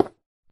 Sound / Minecraft / dig / stone4